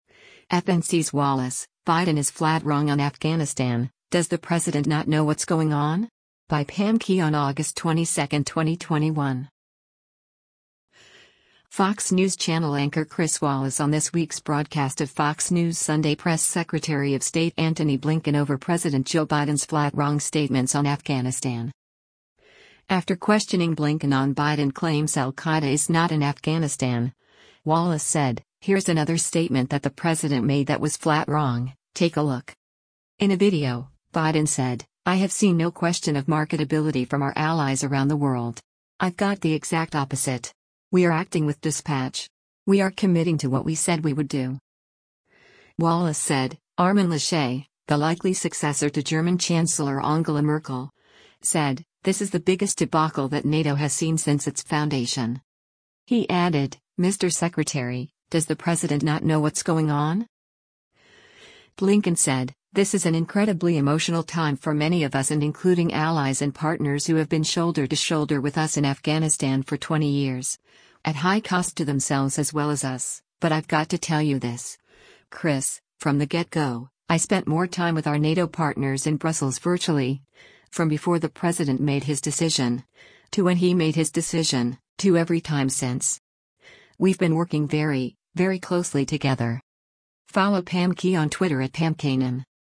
Fox News Channel anchor Chris Wallace on this week’s broadcast of “Fox News Sunday” pressed Secretary of State Antony Blinken over President Joe Biden‘s “flat wrong” statements on Afghanistan.
In a video, Biden said, “I have seen no question of marketability from our allies around the world. I’ve got the exact opposite. We are acting with dispatch. We are committing to what we said we would do.”